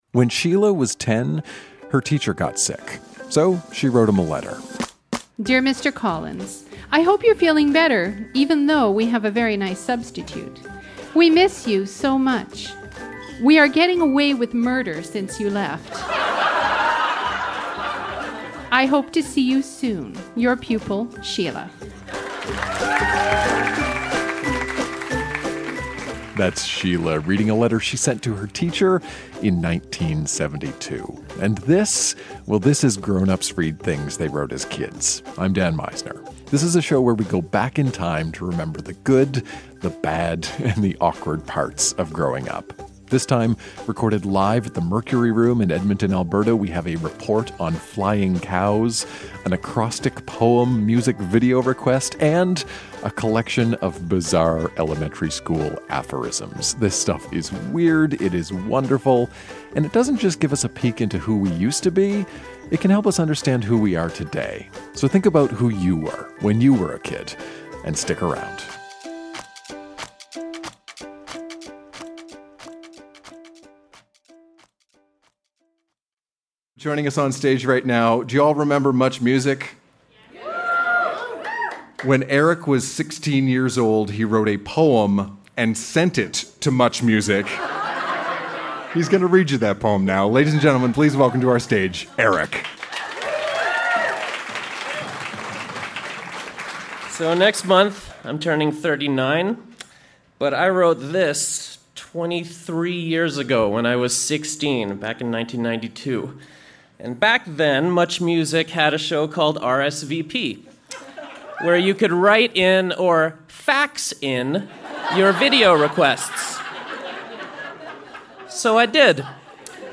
A report on flying cows, an acrostic poem music video request, and a collection of bizarre elementary school aphorisms. Recorded live at the Mercury Room in Edmonton, AB.